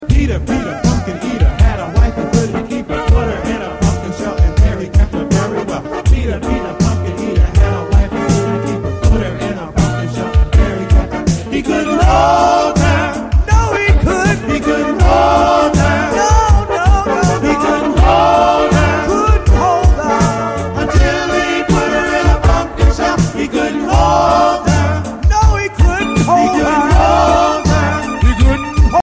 Children's Nursery Rhyme and Sound Clip